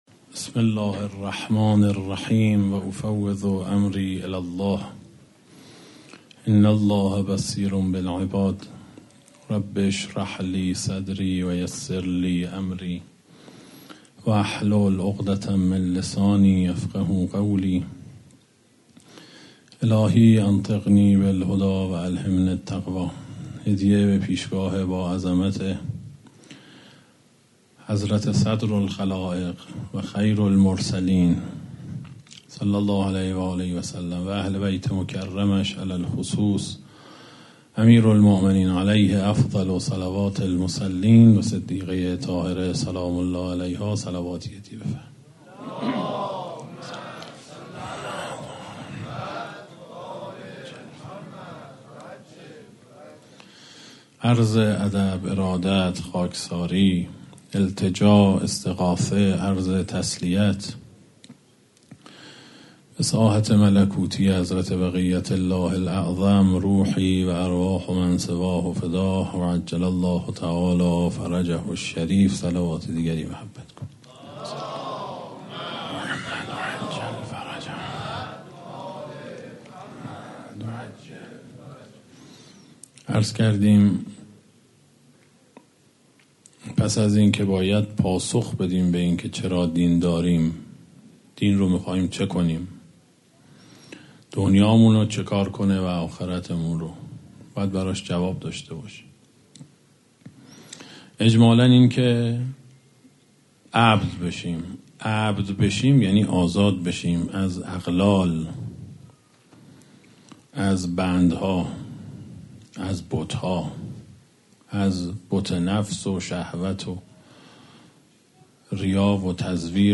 در هیئت محترم عبدالله بن الحسن علیهما السلام تهران